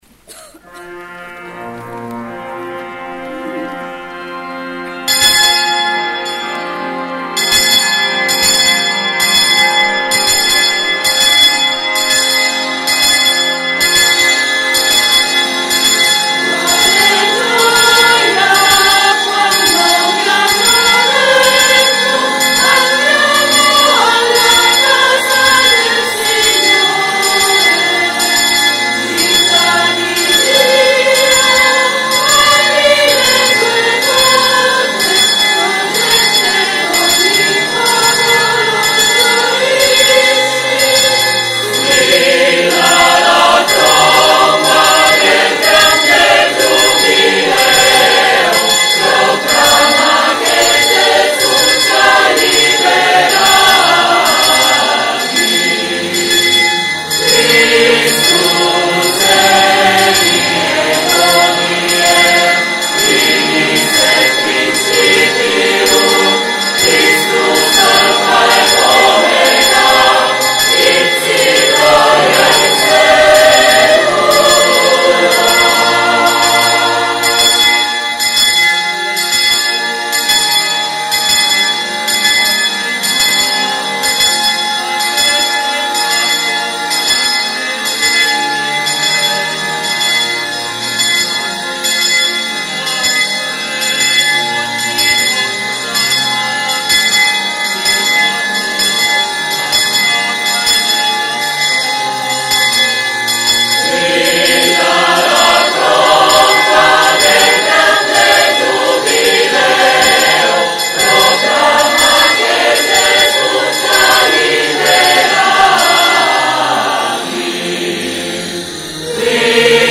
Gallery >> Audio >> Audio2015 >> Apertura Giubileo >> 02-Gb-Inno a Cristo